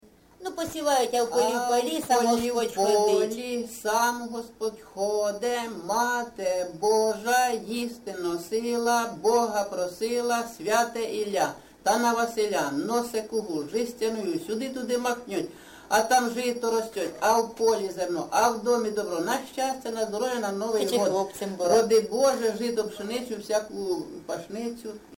ЖанрЩедрівки, Посівальні
Місце записум. Сіверськ, Артемівський (Бахмутський) район, Донецька обл., Україна, Слобожанщина